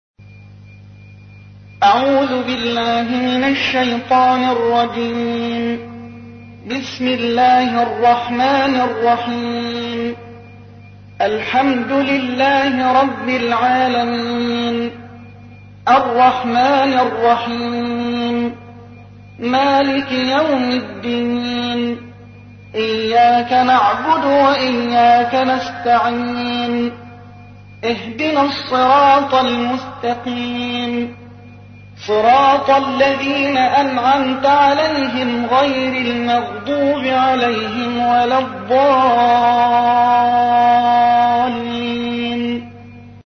سورة الفاتحة / القارئ